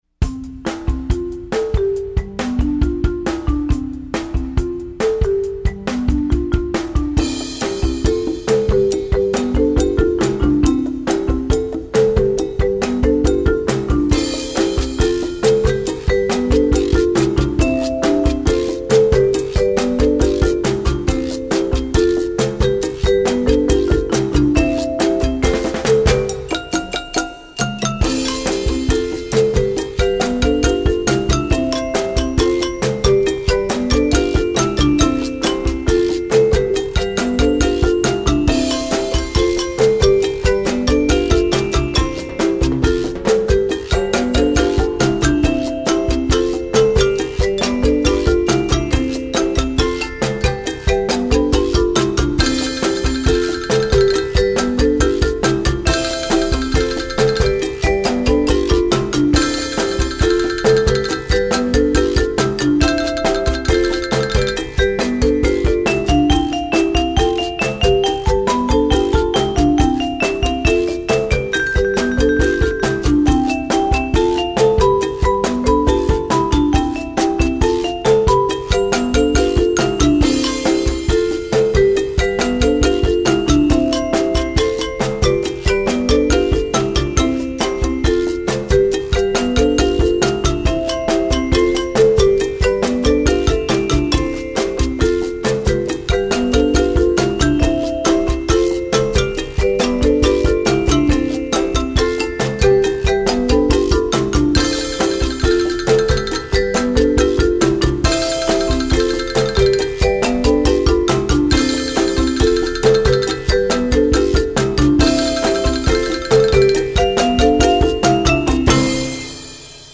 Besetzung: Instrumentalnoten für Schlagzeug/Percussion
MARIMBA 1 (2)
XYLOPHONE 1 (2)
BONGOS (2)
SHAKER (2)
GUIRO (2)
CONGAS (2)
DRUM SET (2)
TIMBALES (2)
VIBES (2)
COWBELL (2)